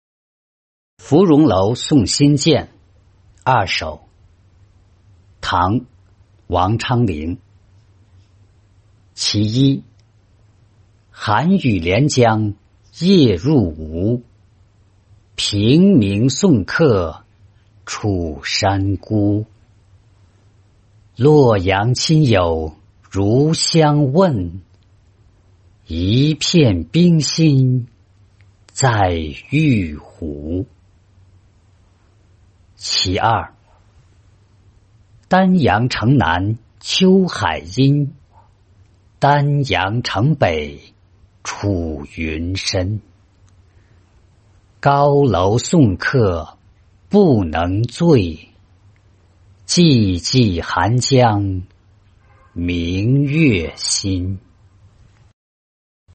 芙蓉楼送辛渐二首-音频朗读